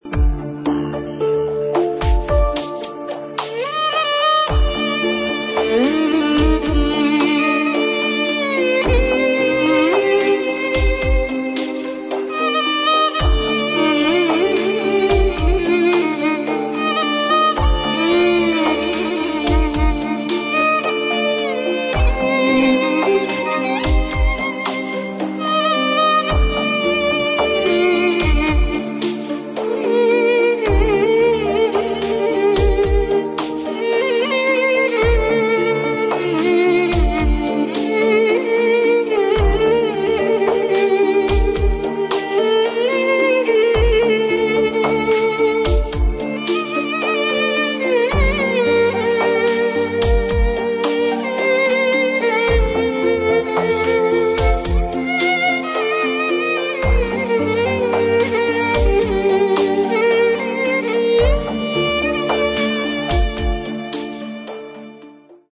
دانلود آهنگ بی کلام تولد امام زمان و نیمه شعبان شاد صوتی
دانلود آهنگ برای تولد امام زمان مبارک بی کلام